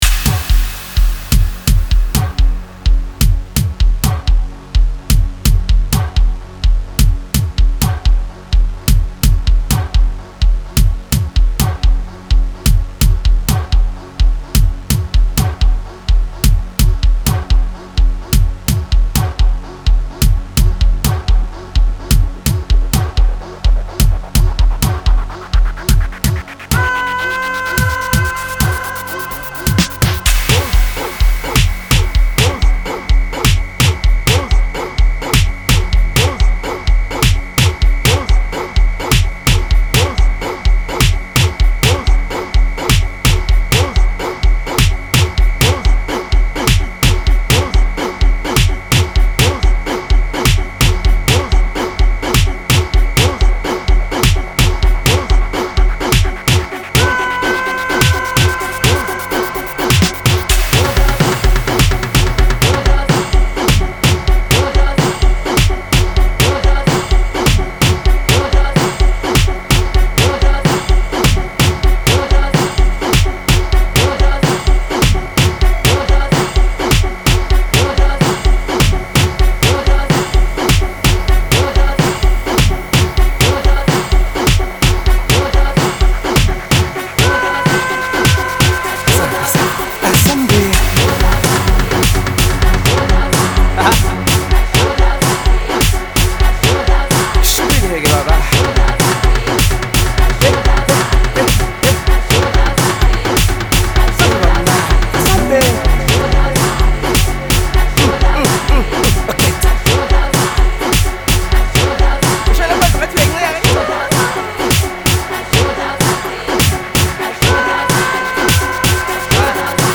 Amapiano Download RECOMENDAÇÕES